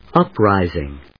音節up・ris・ing 発音記号・読み方
/ˈʌprὰɪzɪŋ(米国英語)/